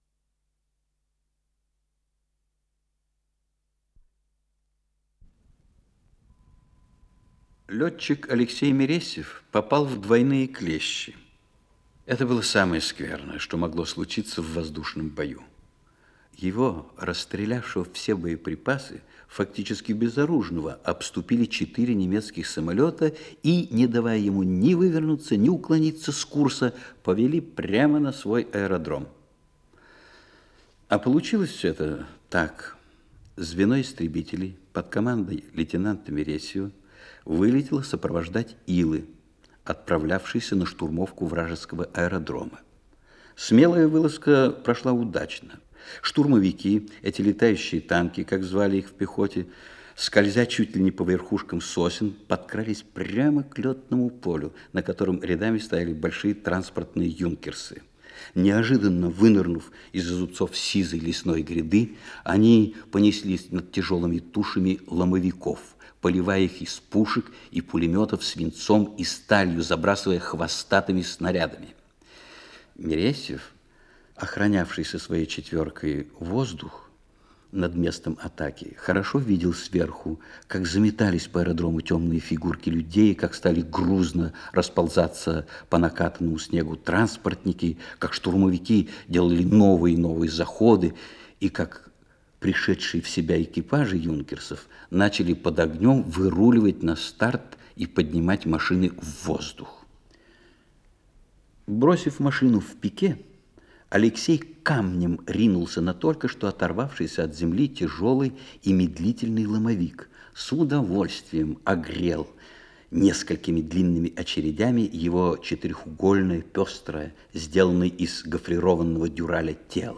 Исполнитель: Павел Кодочников - чтение
Радиокомпозиция в 6-ти частях, часть 1-я